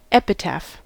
Ääntäminen
US Tuntematon aksentti: IPA : /ˈɛpɪtæf/